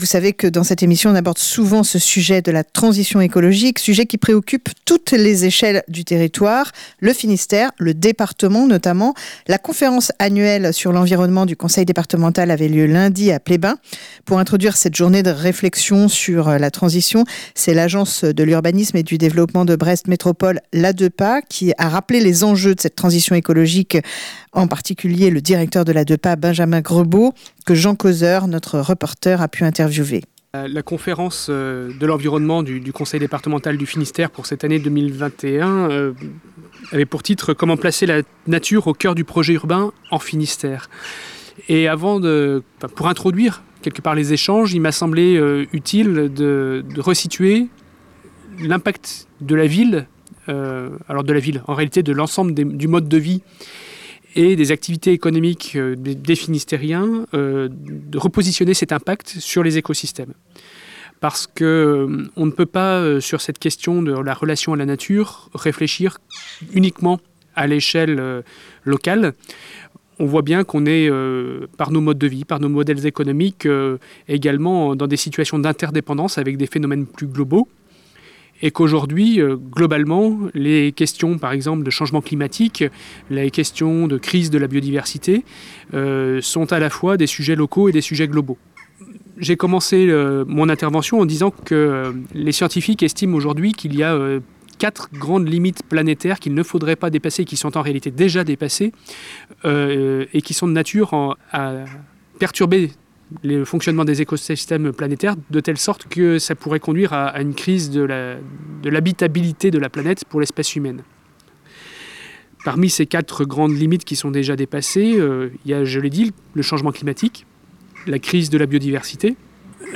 LEMRUB-transition-ecologique-en-Finistere-Adeupa.mp3